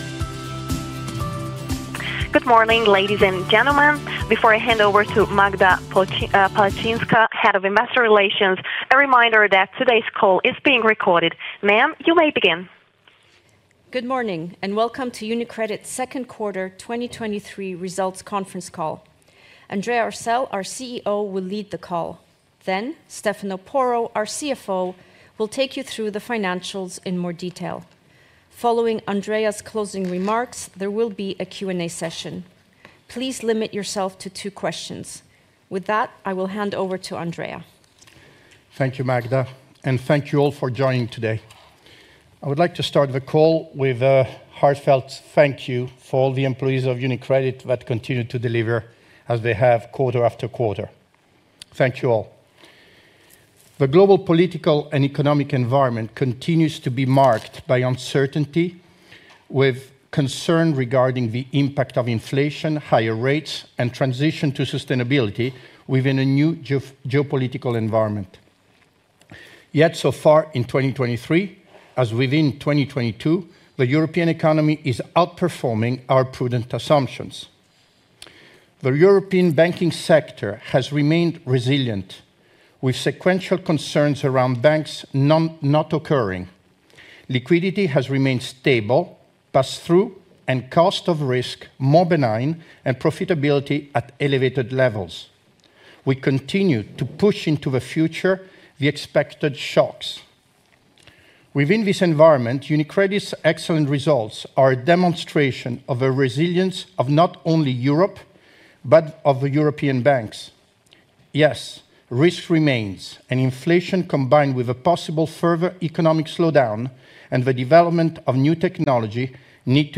2Q23 & 1H23 Group Results Presentation (publication and conference call)